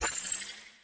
powerup.mp3